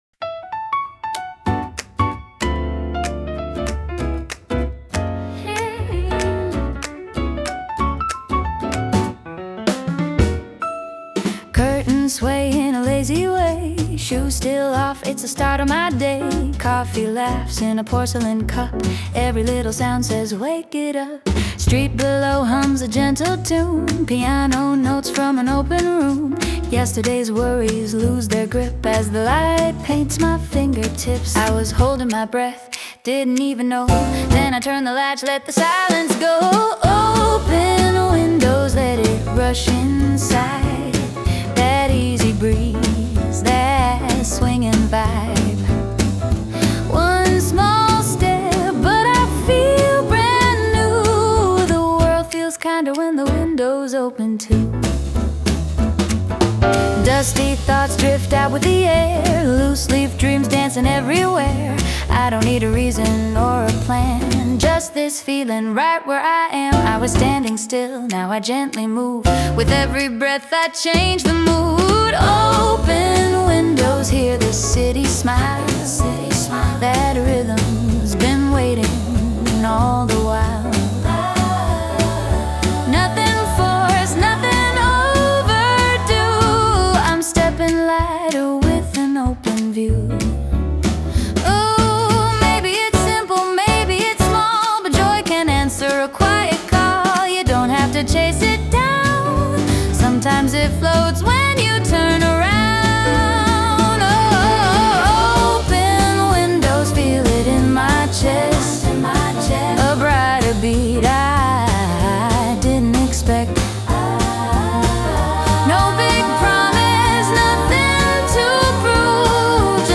洋楽女性ボーカル著作権フリーBGM ボーカル
著作権フリーオリジナルBGMです。
女性ボーカル（洋楽・英語）曲です。
軽め爽やかジャズを目指しました✨